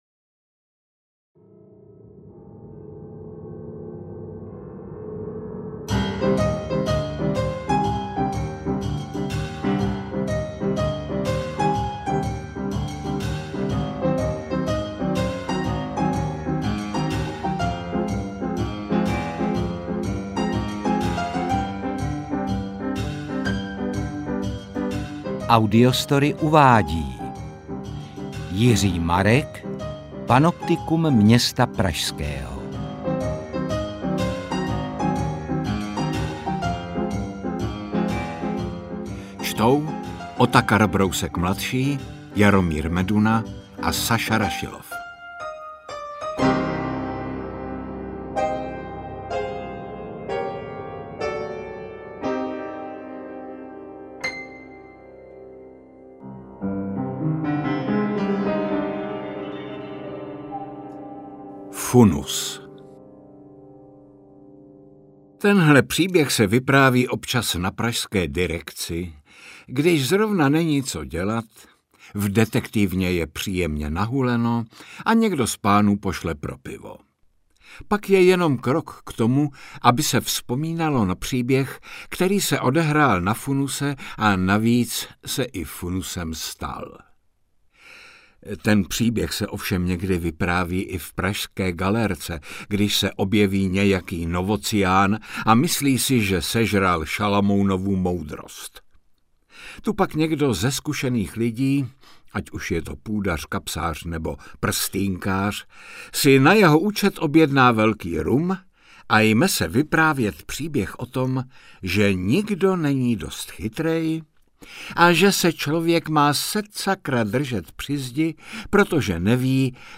Interpreti:  Otakar Brousek ml., Jaromír Meduna, Saša Rašilov
AudioKniha ke stažení, 23 x mp3, délka 10 hod. 35 min., velikost 581,1 MB, česky